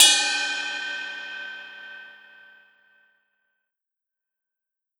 Crashes & Cymbals
22inchbell.wav